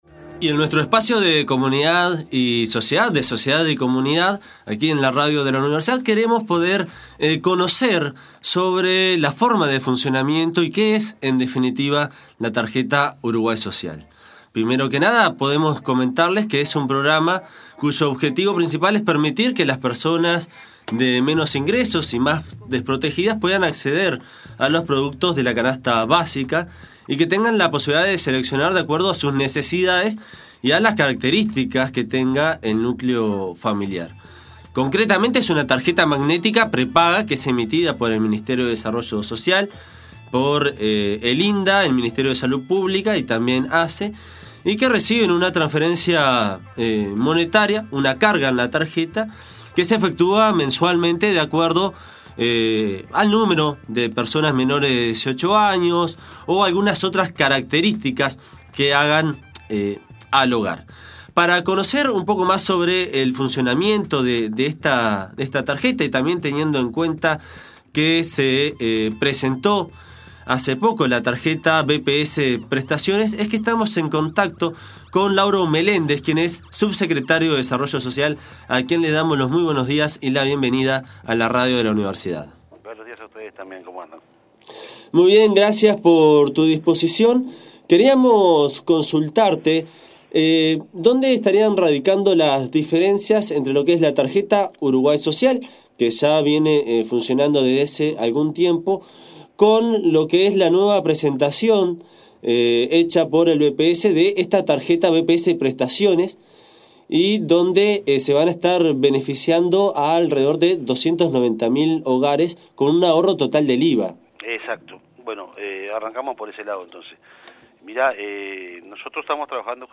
En su Espacio de Comunidad y Sociedad, UNI Radio conversó con Lauro Meléndez, Subsectretario del Ministerio de Desarrollo Social, sobre dos programas que utilizan tarjetas electrónicas como forma de brindar prestaciones sociales a dos grupos objetivos deferentes.